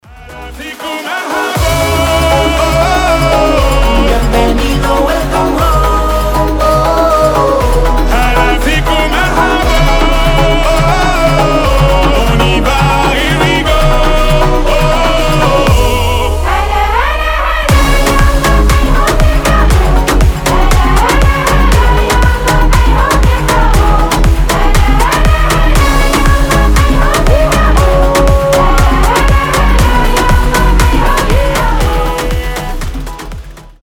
танцевальные
вдохновляющие
арабские , dancehall